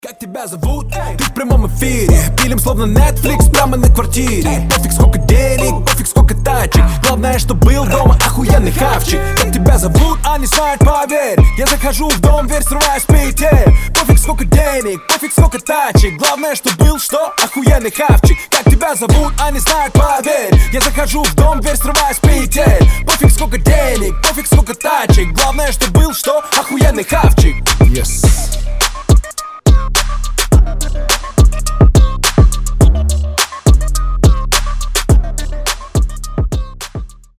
• Качество: 320, Stereo
крутые
мощные басы
качающие
мужские